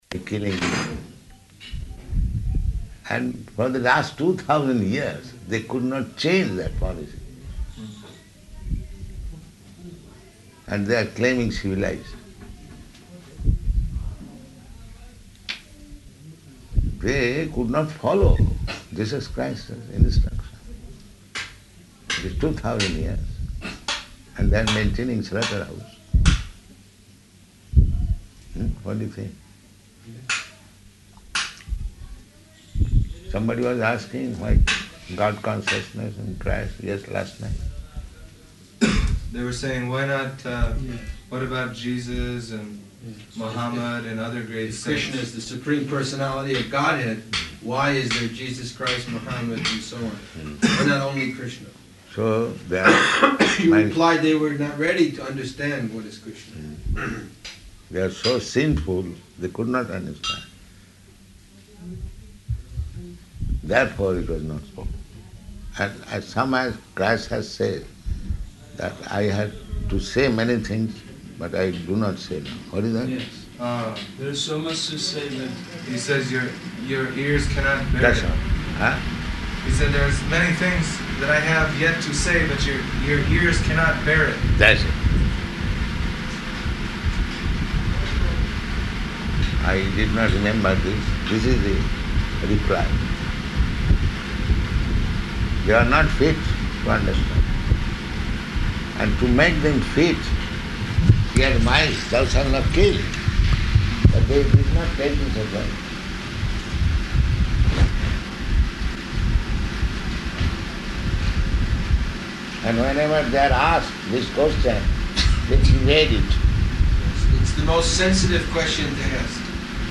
Room Conversation
Location: Hyderabad